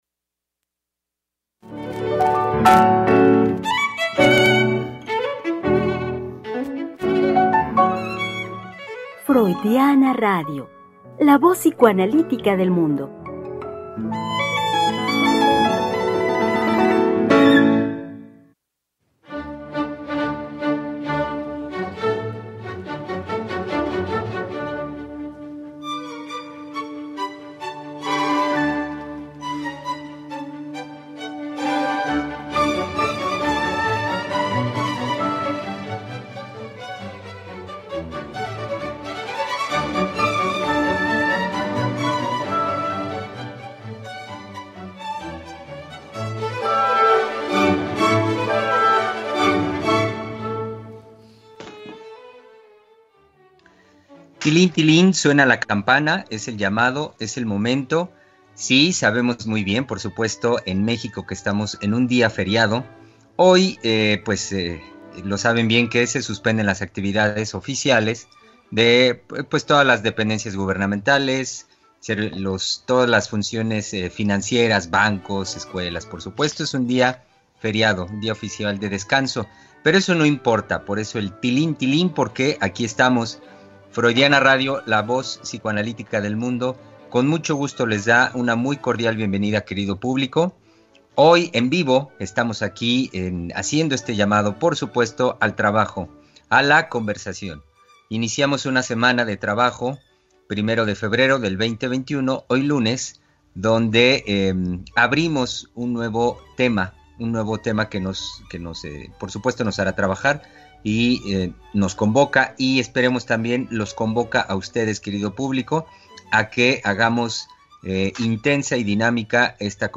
Tres Mujeres Psicoanalistas Hablando de la Vida Cotidiana.